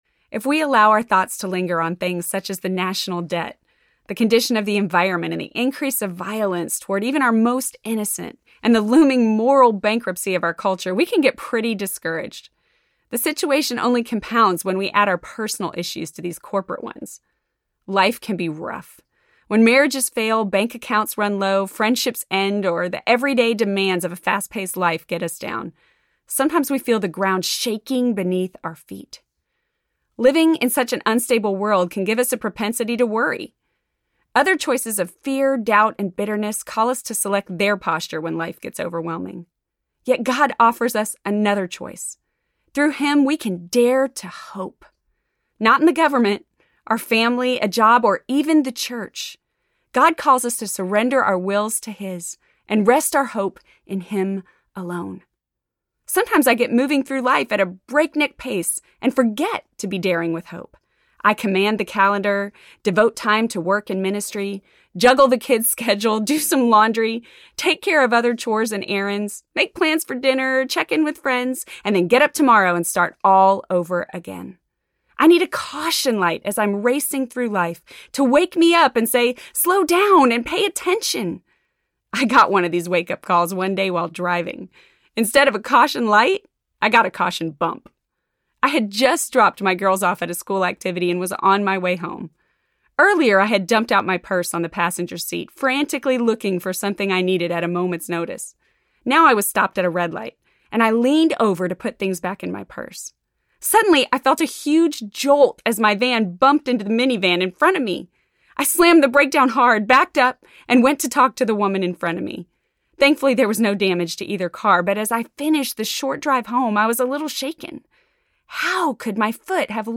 Dare to Hope Audiobook
Narrator
4.8 Hrs. – Unabridged